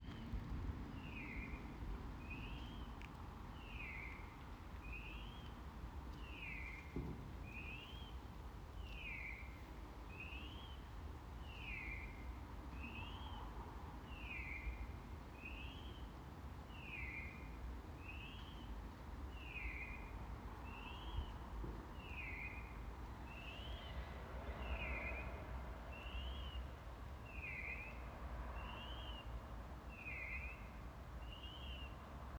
Very built up suburban area in Yorkshire, no water nearby. Went on for about 20 minutes after 8:30pm and then haven’t heard it since.
Night time bird call in Yorkshire - alarm-like!
Bird-call.wav